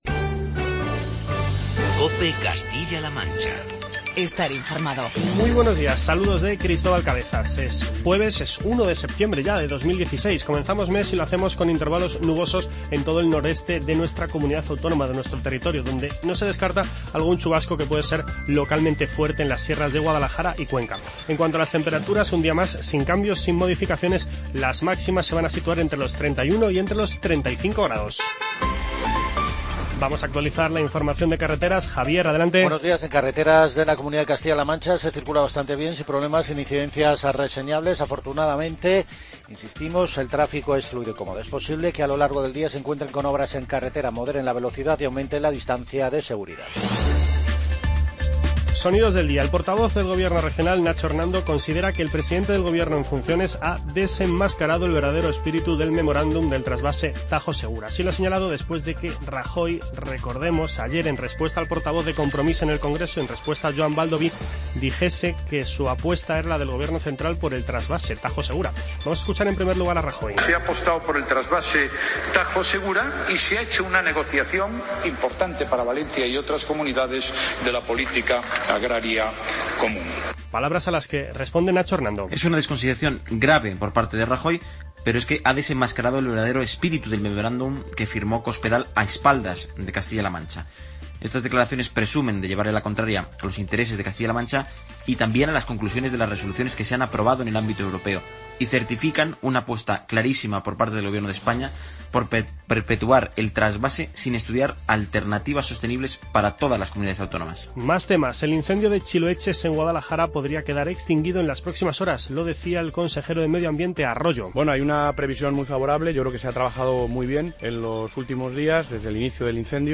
Informativo regional
Escuche las declaraciones de Mariano Rajoy, Nacho Hernando y Francisco Martínez Arroyo.